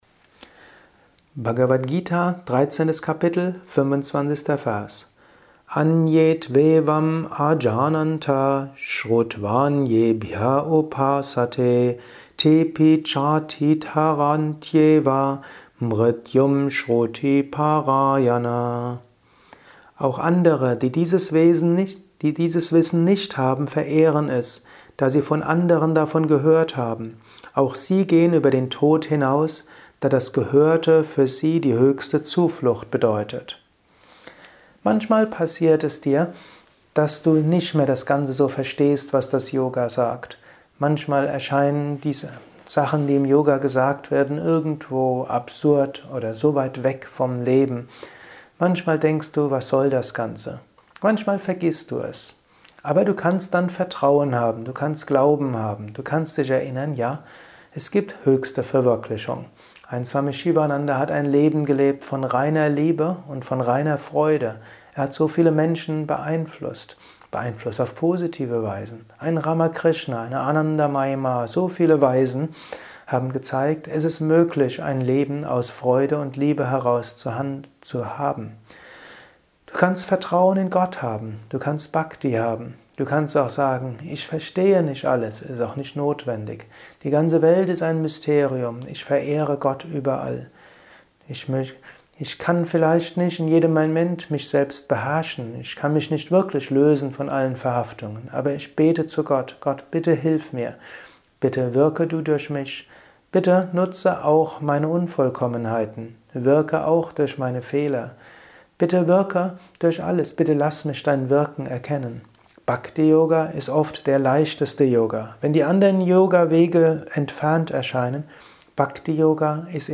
Kurzvorträge
Dies ist ein kurzer Kommentar als Inspiration für den heutigen